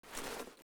open_empty.ogg